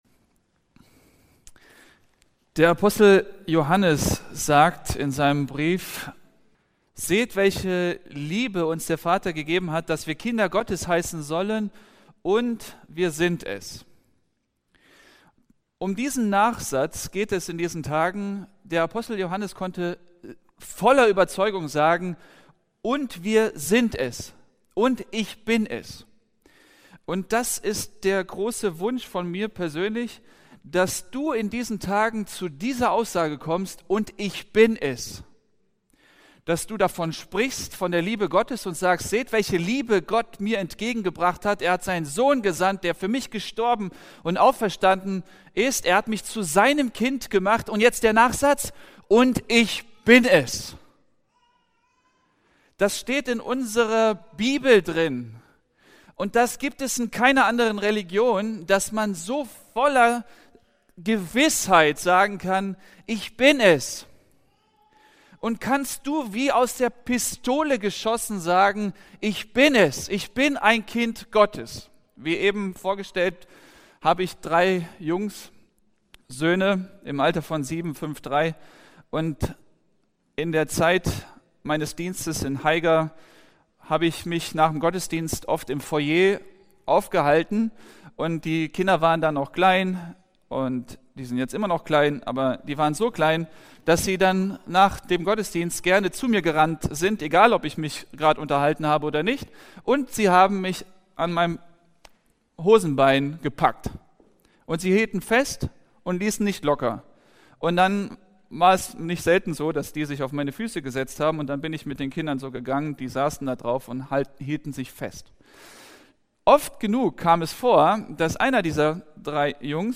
November 2019 Predigt-Reihe